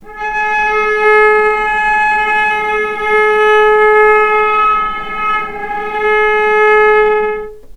healing-soundscapes/Sound Banks/HSS_OP_Pack/Strings/cello/sul-ponticello/vc_sp-G#4-mf.AIF at bf8b0d83acd083cad68aa8590bc4568aa0baec05
vc_sp-G#4-mf.AIF